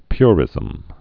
(pyrĭzəm)